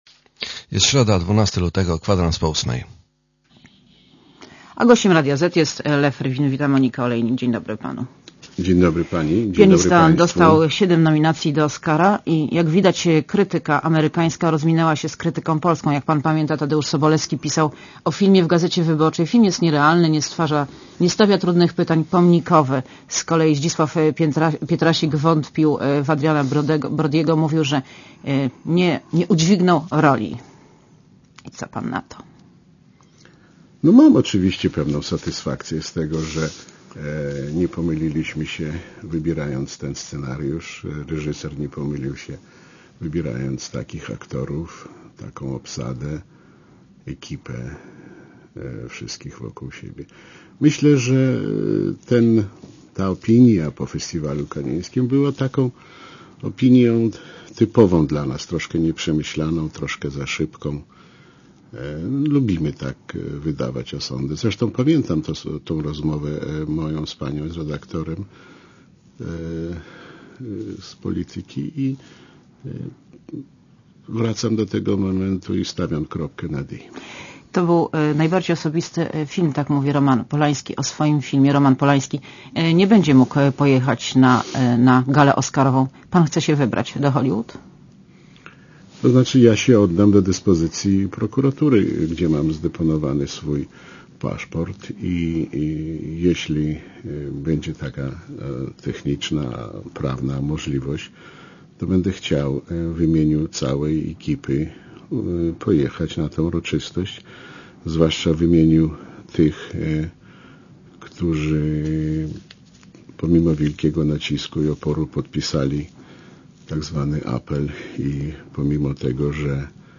Monika Olejnik rozmawia z Lwem Rywinem - współproducentem filmu "Pianista"
(PAP) Źródło: (PAP) (RadioZet) Źródło: (RadioZet) Posłuchaj wywiadu (3,7 MB) „Pianista” dostał siedem nominacji do Oscara - jak widać, krytyka amerykańska rozminęła się z krytyką polską.